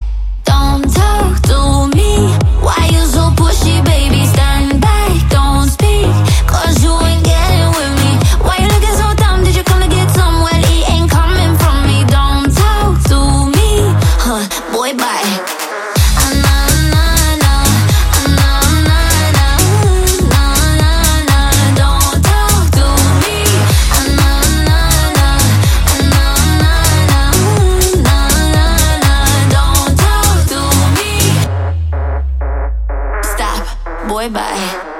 танцевальные , зажигательные
house